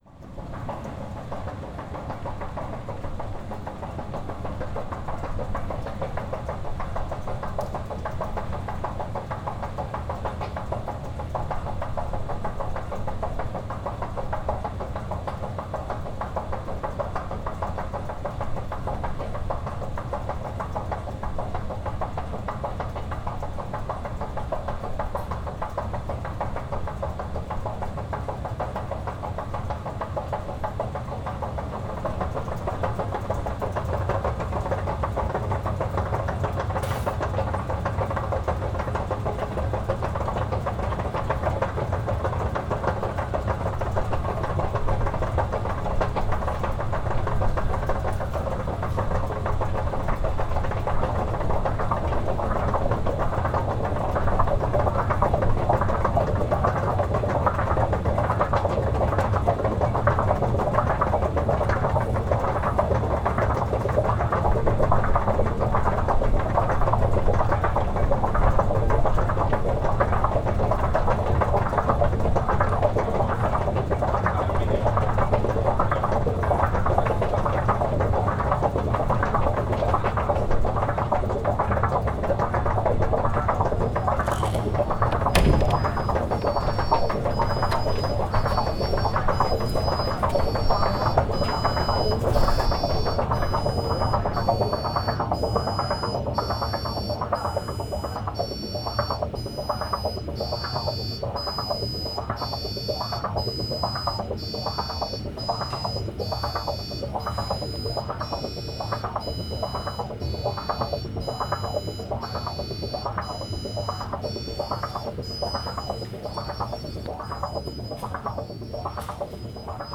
field recordings, sound art, radio, sound walks
Tagy: stroje podzemí doprava
Na stanici Náměstí Míru klapají pražské nejhlubší eskalátory: jsou dlouhé 87 metrů a s hloubkovým rozdílem 43 metrů. Jejich perkusivní charakter je podivuhodný.
Pohyblivé schody v metru jsou naštěstí můzické a jejich hlas a rytmy se mění podle toho jak jsou preparované.